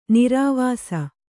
♪ nirāvāsa